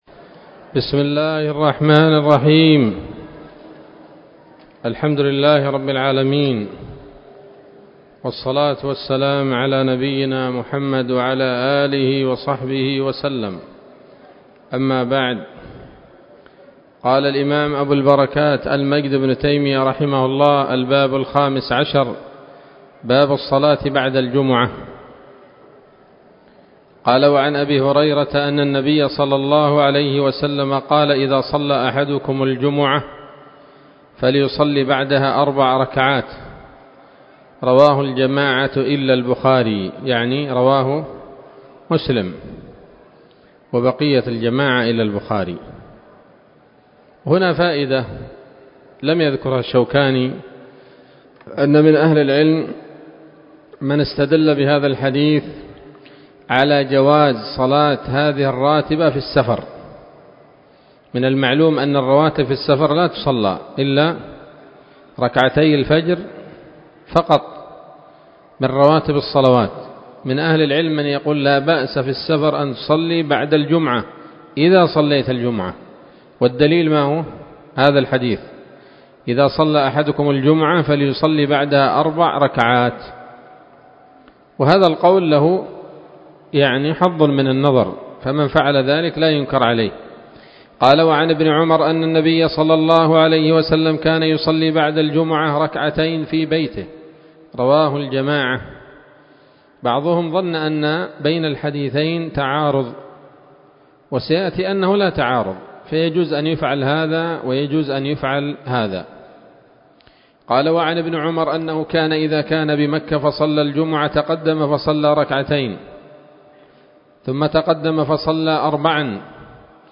الدرس الرابع والثلاثون من ‌‌‌‌أَبْوَاب الجمعة من نيل الأوطار